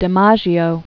(də-mäzhē-ō, -măjē-ō), Joseph Paul Known as "Jolting Joe" or and "the Yankee Clipper." 1914-1999.